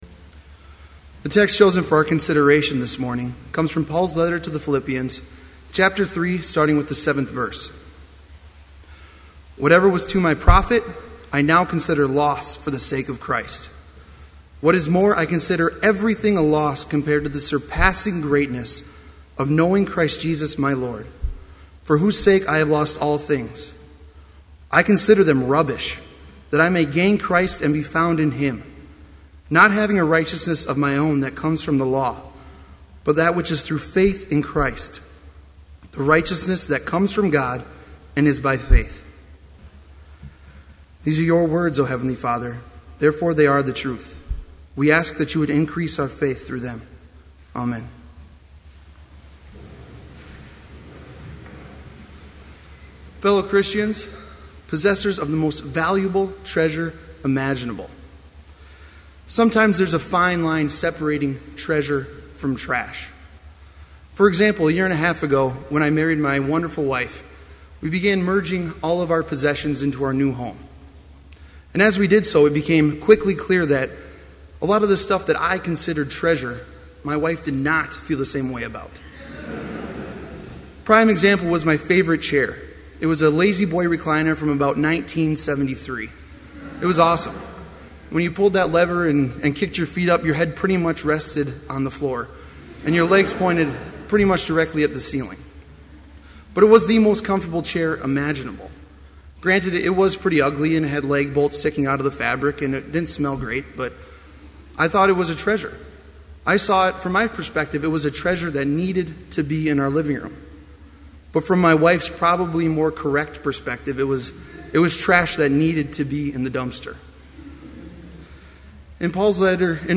Complete Service
• Homily
This Chapel Service was held in Trinity Chapel at Bethany Lutheran College on Tuesday, March 20, 2012, at 10 a.m. Page and hymn numbers are from the Evangelical Lutheran Hymnary.